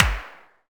clapOnbeat1.ogg